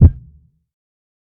TC3Kick15.wav